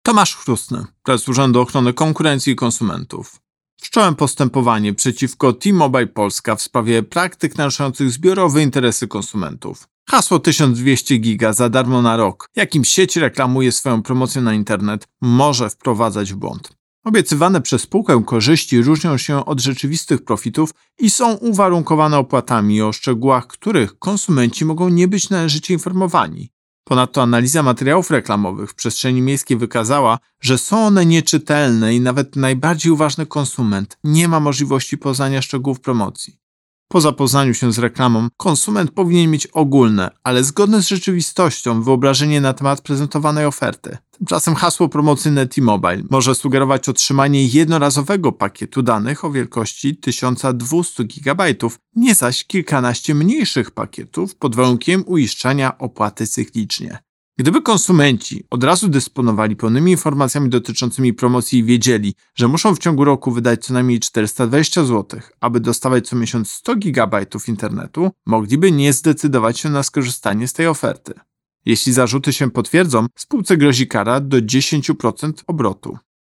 Wypowiedź Prezesa UOKiK Tomasza Chróstnego z 2 stycznia 2023 r..mp3